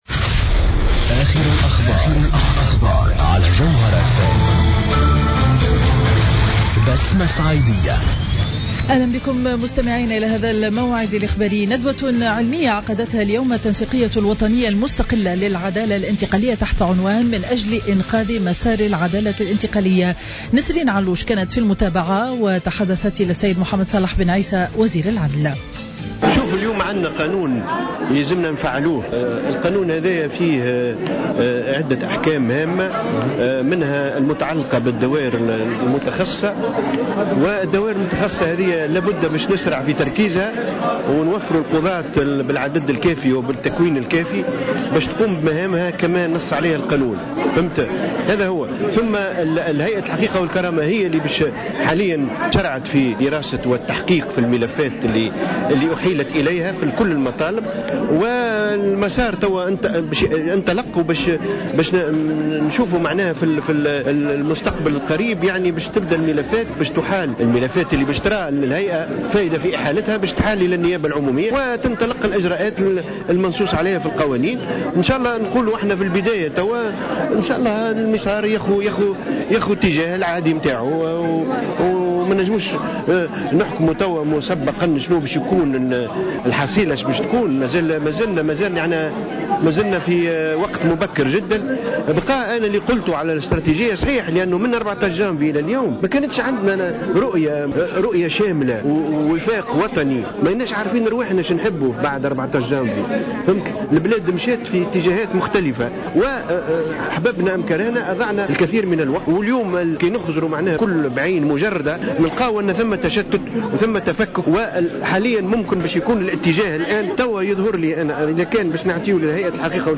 نشرة الأخبار منتصف النهار ليوم الخميس 04 جوان 2015